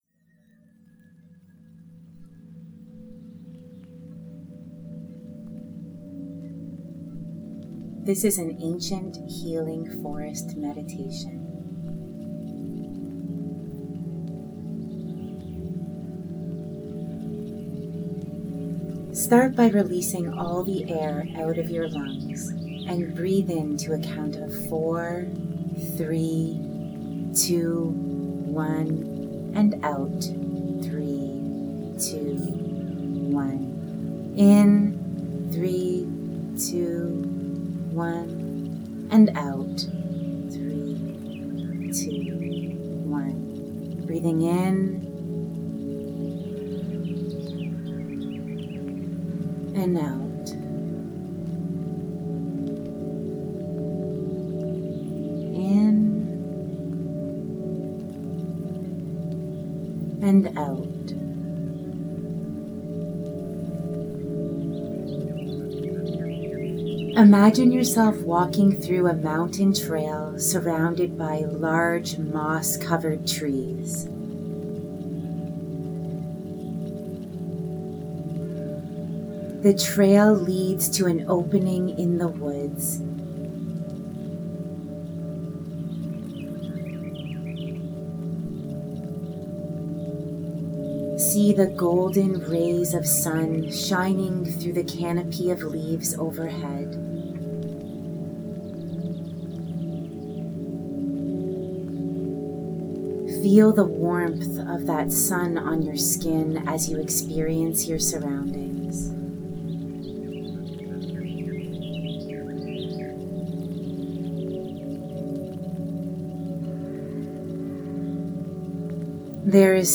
This is a healing meditation to help you release any physical, mental, or emotional pain.